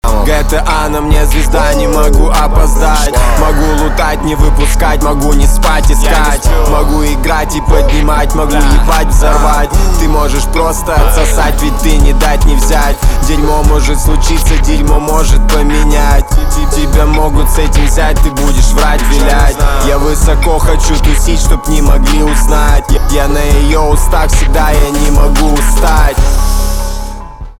русский рэп
басы , качающие
жесткие